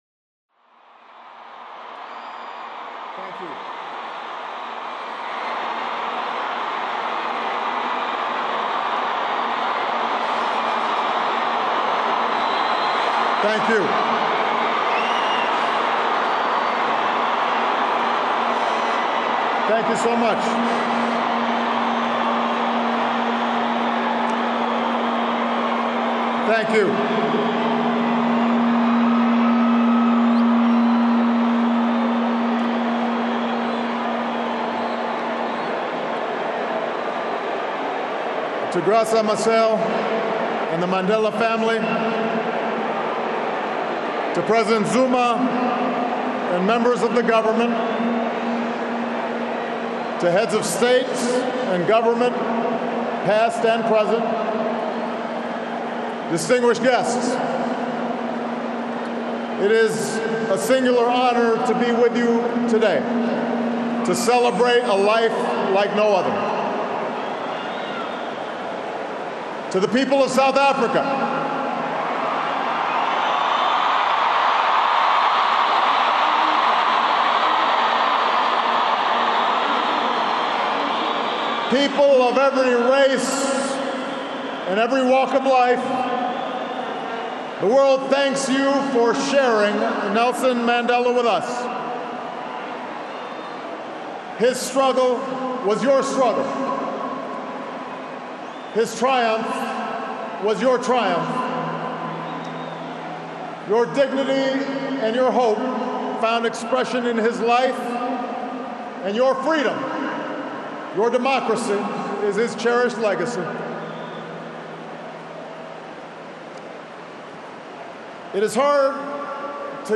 U.S. President Obama delivers remarks at a national memorial service for former South African President Nelson Mandela